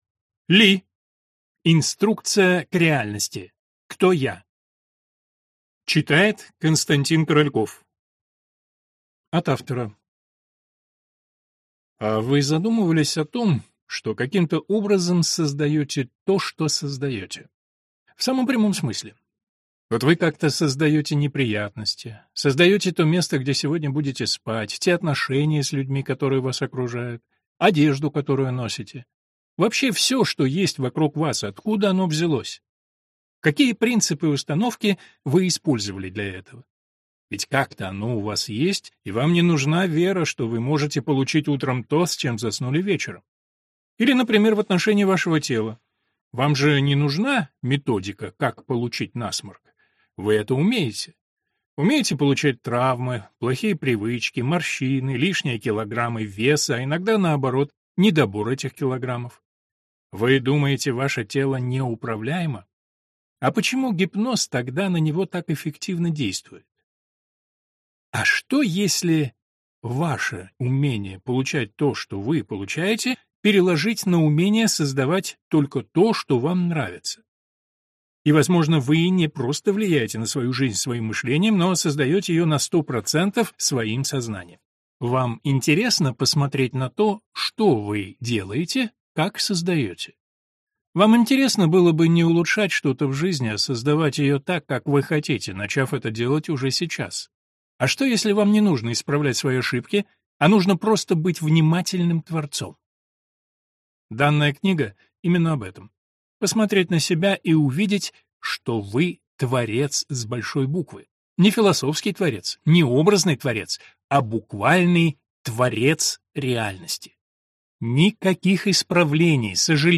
Аудиокнига Кто я? Инструкция к реальности | Библиотека аудиокниг